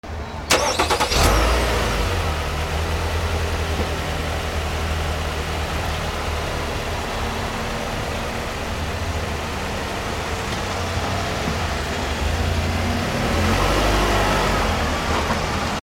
200 車 エンジンをかける
/ E｜乗り物 / E-10 ｜自動車